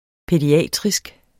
Udtale [ pεdiˈæˀtʁisg ]